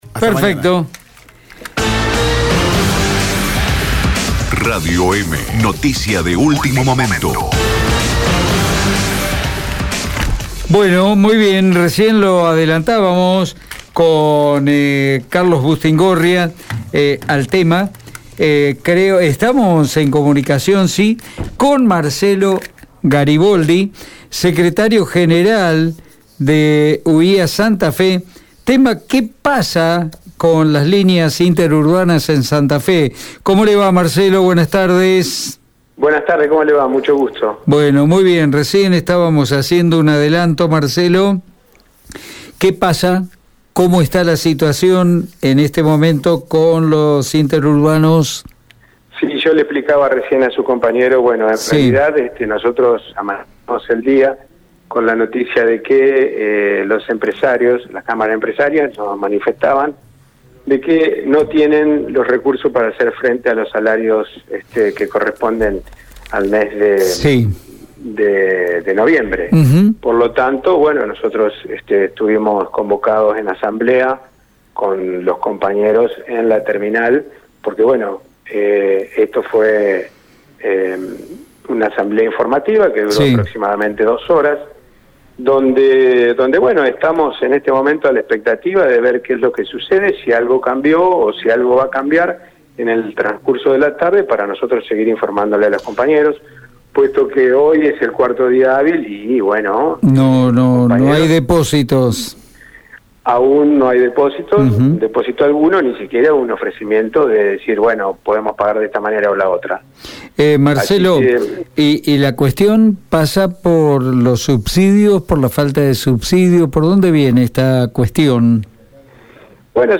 Por otro lado, el entrevistado remarcó que «Ahora estamos a la expectativa de ver lo que sucede.